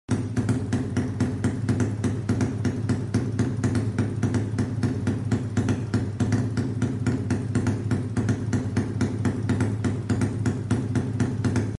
gini bukan sih suara knalpot anak" proper.